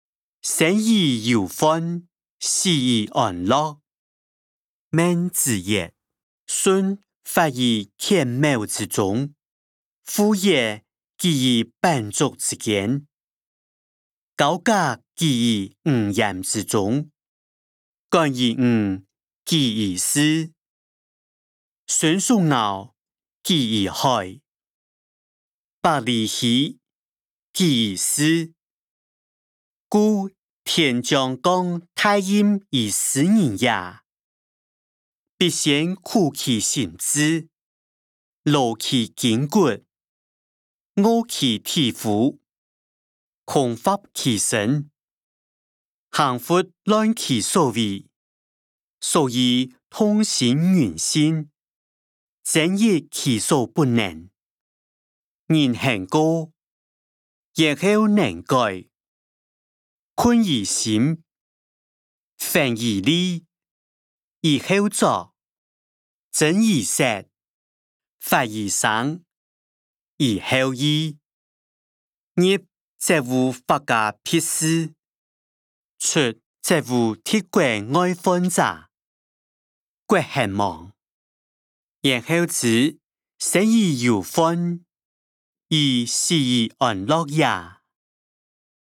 經學、論孟-生於憂患，死於安樂音檔(四縣腔)